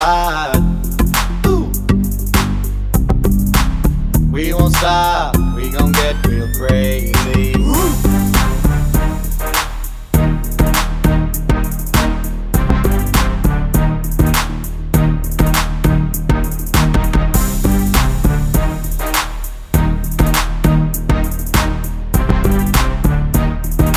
for solo male R'n'B / Hip Hop 4:12 Buy £1.50